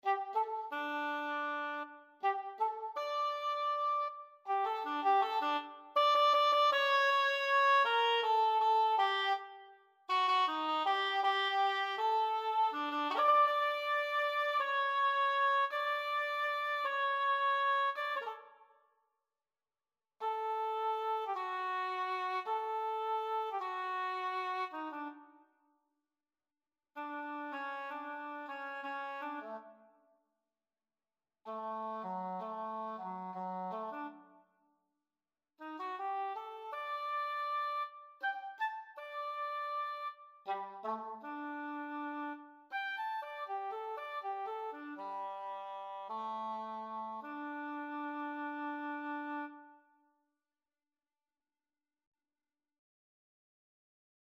The task for this project was to compose nine pieces for woodwind instruments for nine different scales.
english-horn-audio.mp3